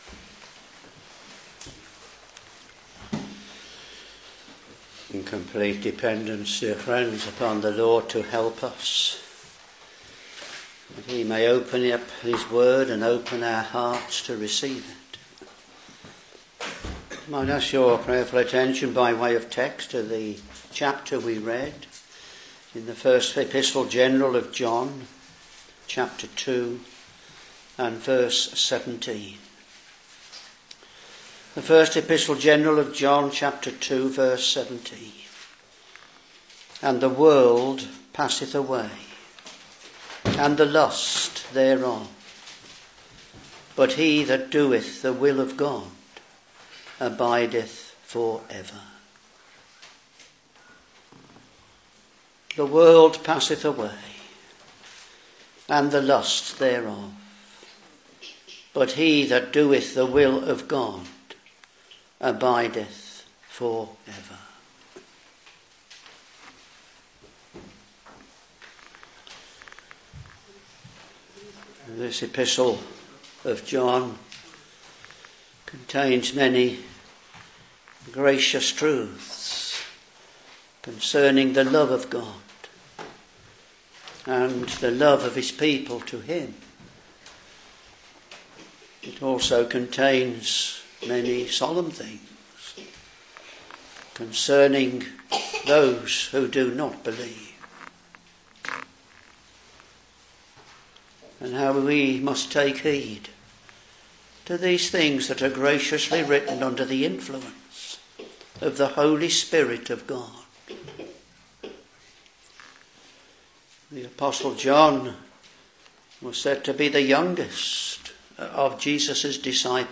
Back to Sermons 1 John Ch.2 v.17 And the world passeth away, and the lust thereof: but he that doeth the will of God abideth for ever.